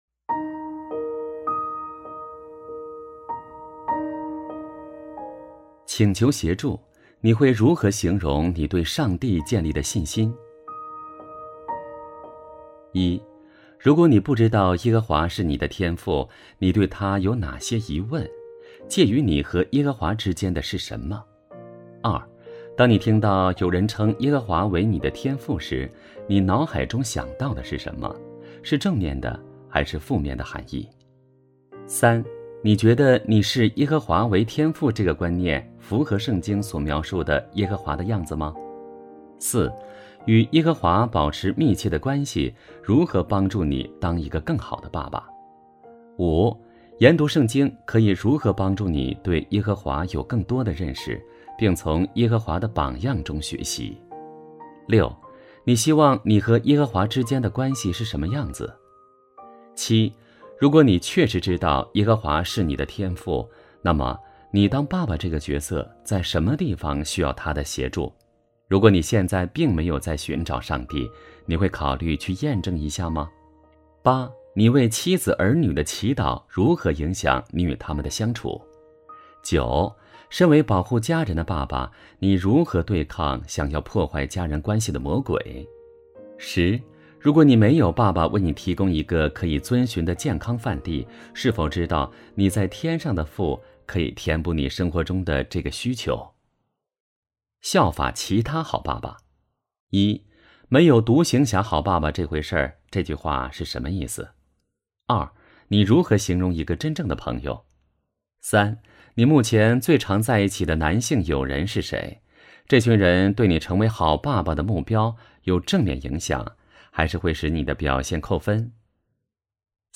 首页 > 有声书 > 婚姻家庭 | 成就好爸爸 | 有声书 > 成就好爸爸：44 请求协助 你会如何形容你对上帝建立的信心？